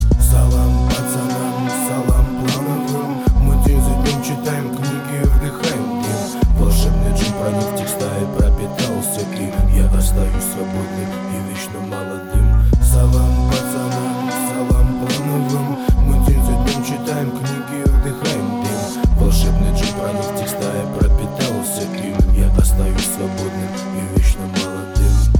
• Качество: 320, Stereo
восточные мотивы
русский рэп
спокойные